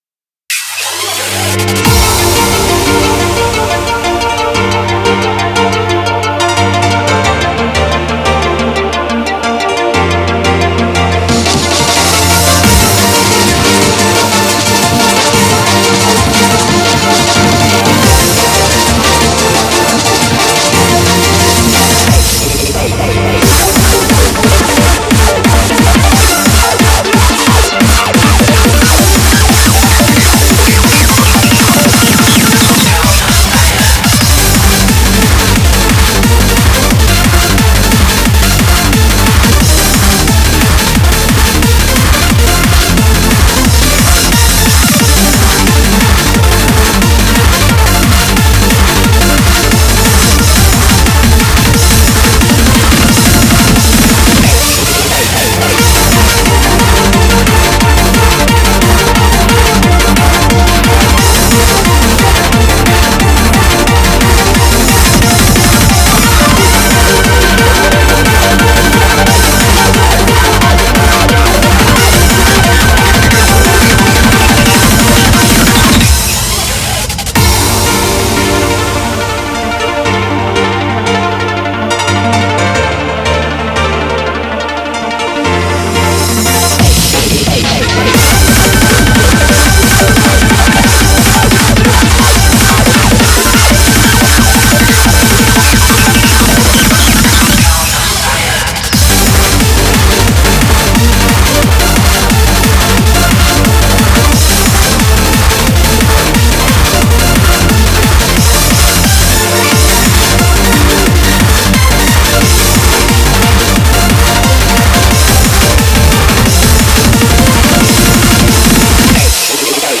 BPM178
Audio QualityPerfect (High Quality)